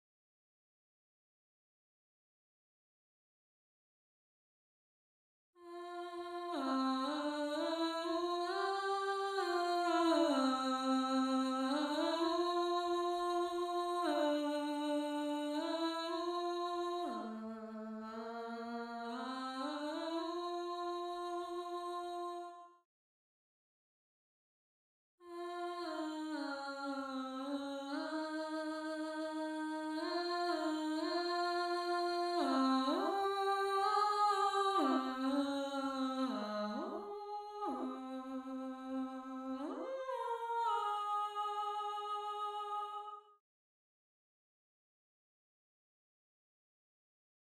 Voice 4 (Alto/Alto)
gallon-v8sp5-21-Alto_1.mp3